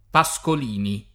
[ pa S kol & ni ]